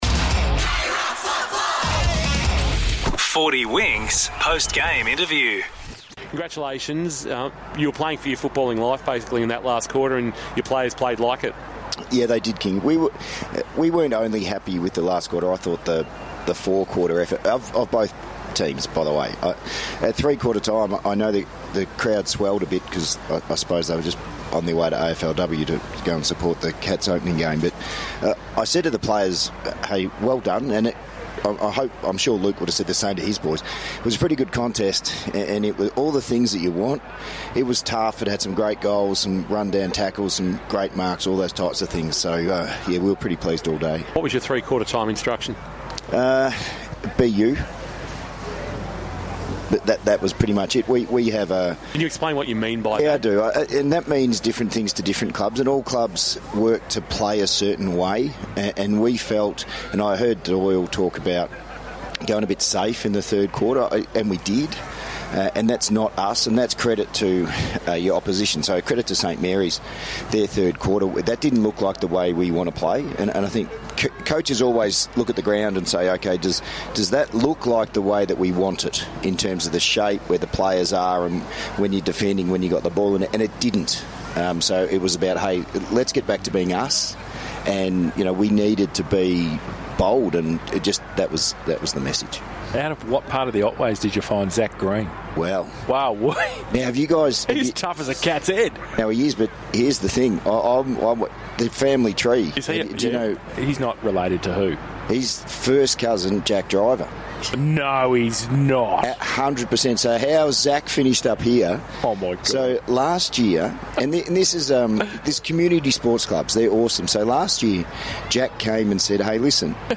2025 - GFNL - Round 16 - St Mary's vs. South Barwon - Post-match interview: Mark Neeld (South Barwon coach)